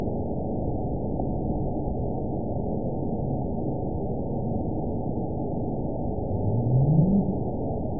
event 917692 date 04/12/23 time 23:23:21 GMT (2 years, 1 month ago) score 9.40 location TSS-AB01 detected by nrw target species NRW annotations +NRW Spectrogram: Frequency (kHz) vs. Time (s) audio not available .wav